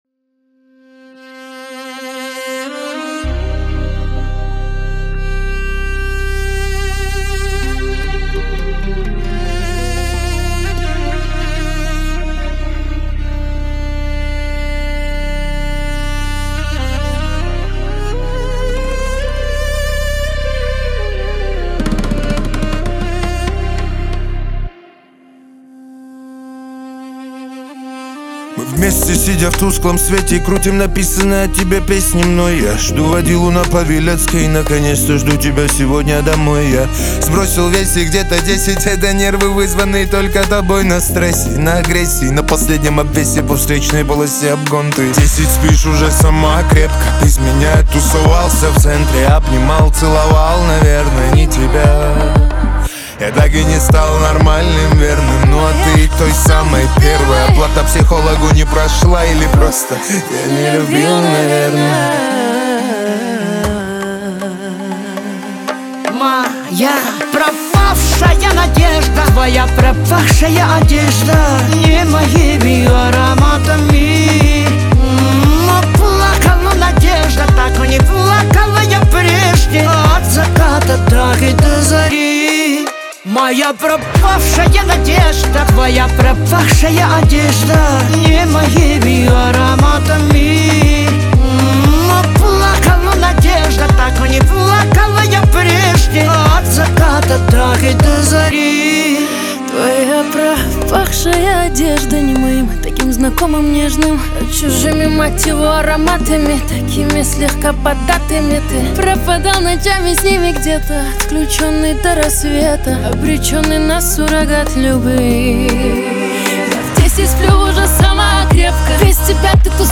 Поп музыка, Русские треки, Русские поп песни, 2026